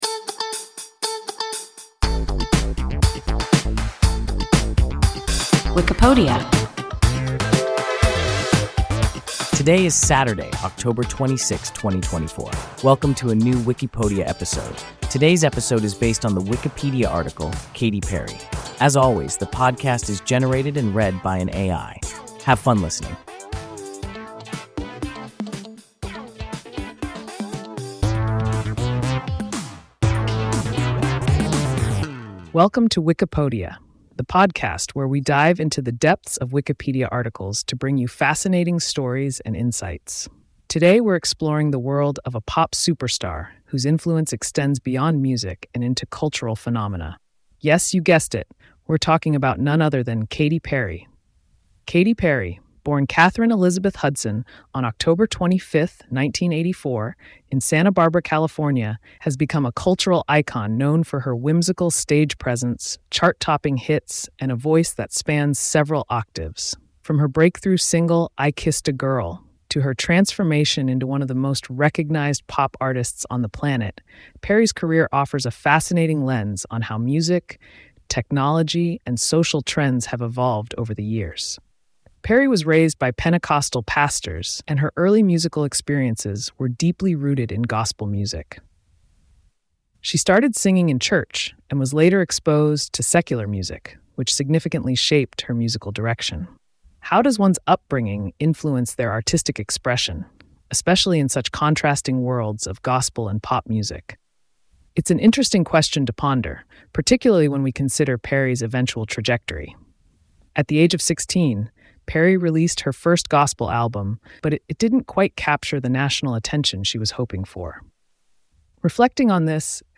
Katy Perry – WIKIPODIA – ein KI Podcast